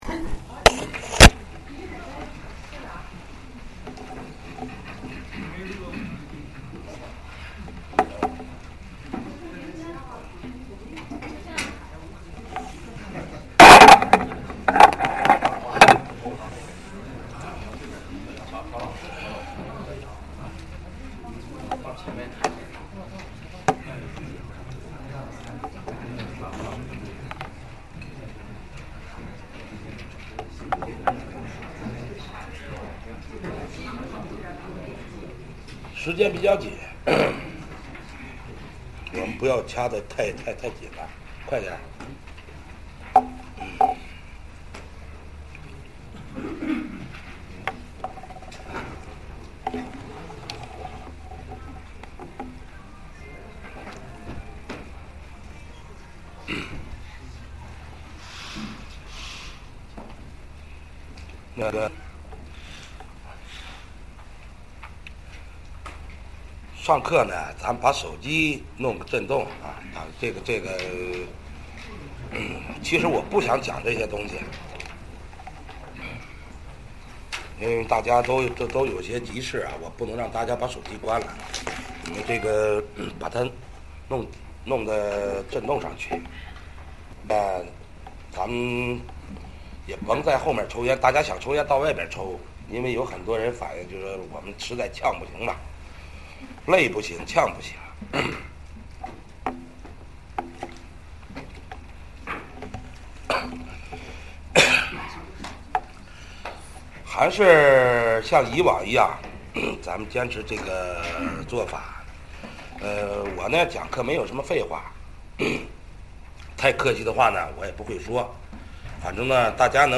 风水课程